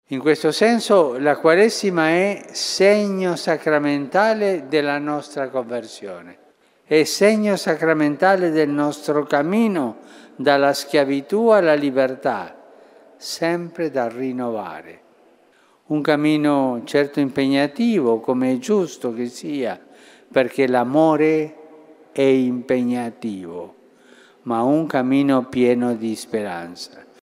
O Wielkim Poście, jako pielgrzymce nadziei, mówił papież Franciszek podczas audiencji ogólnej.